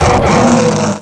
pokeemerald / sound / direct_sound_samples / cries / zweilous.aif